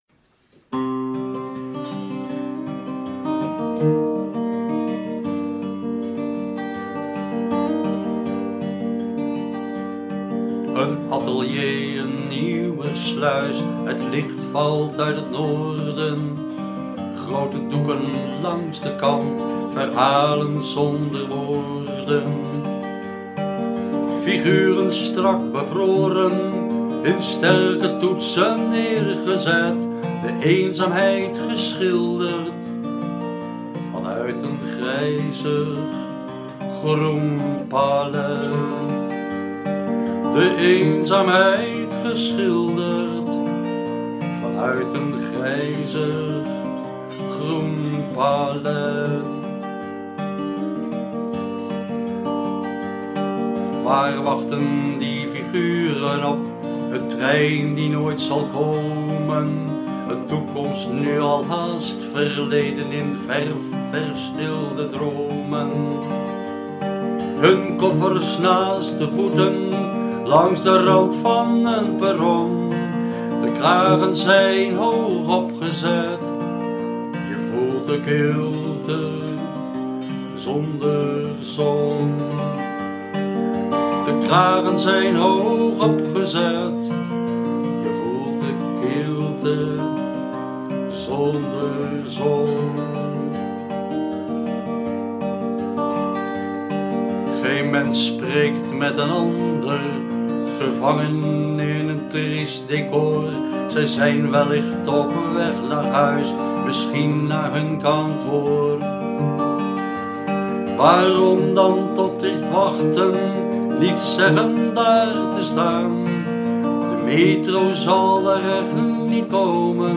Muzikale omlijsting met o.a. een hommage aan Willem G. van de Hulst met
Live-opname iPhone